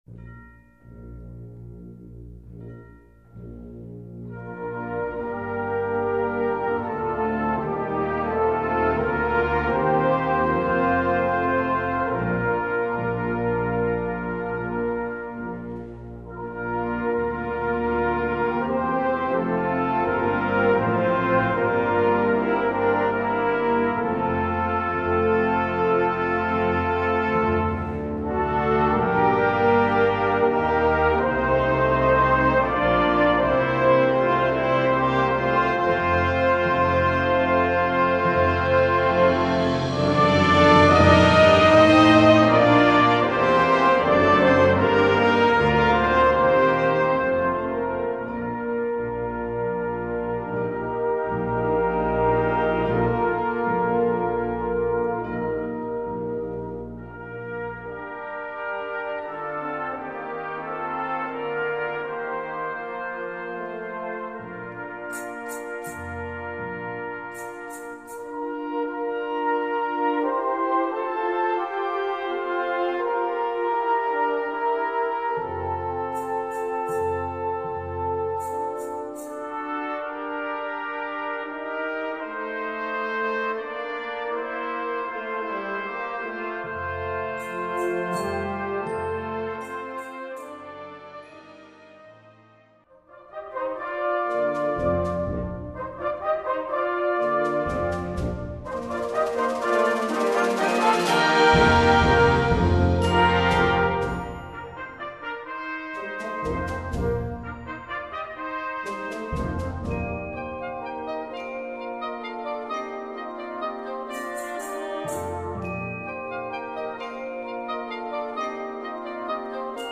Répertoire pour Harmonie/fanfare - Fanfare